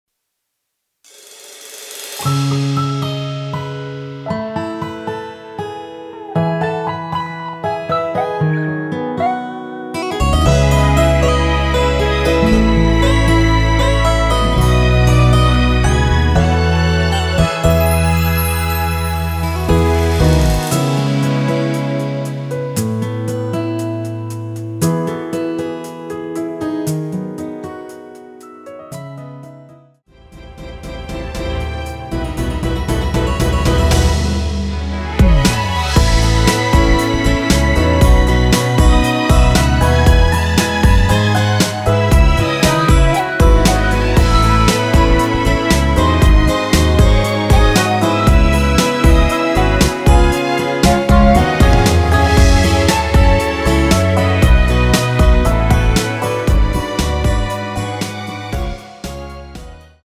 여성분이 부르실수 있는 키의 MR입니다.
C#m
앞부분30초, 뒷부분30초씩 편집해서 올려 드리고 있습니다.
중간에 음이 끈어지고 다시 나오는 이유는